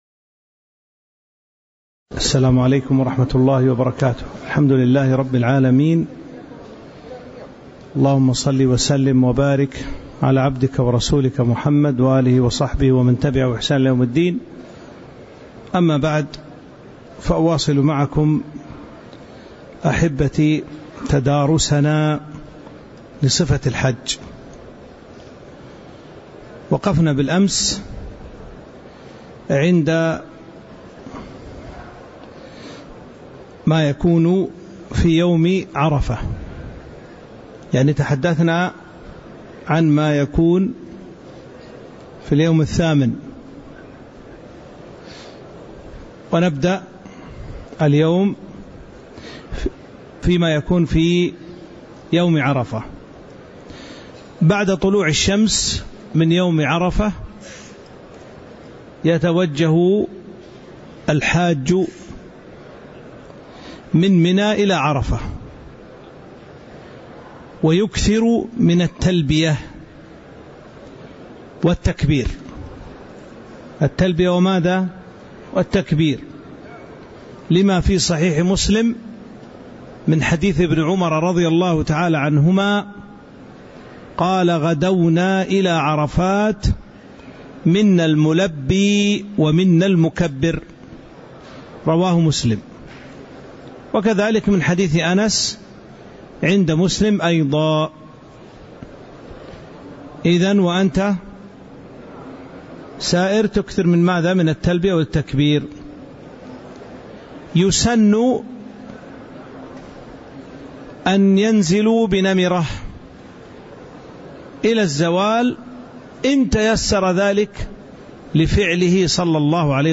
تاريخ النشر ٢٧ ذو القعدة ١٤٤٥ هـ المكان: المسجد النبوي الشيخ